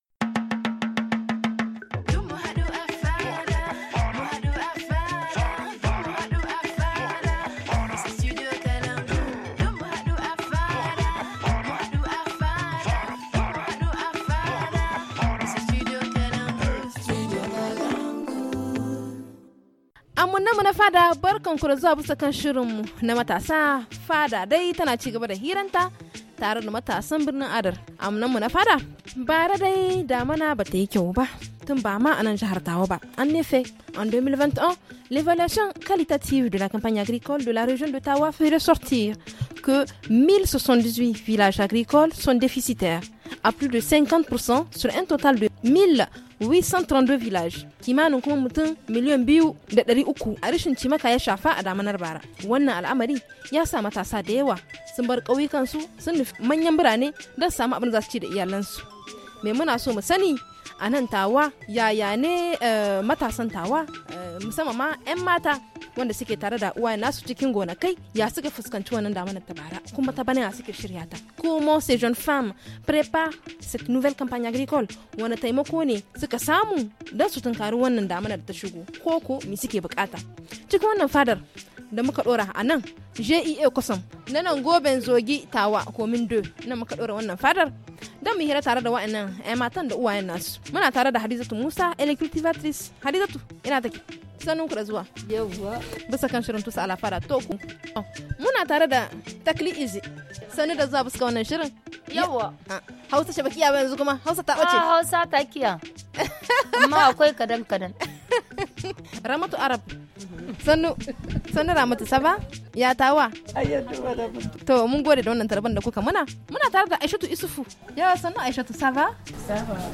Installés à la coopérative du groupement Kossom , la fada accueille :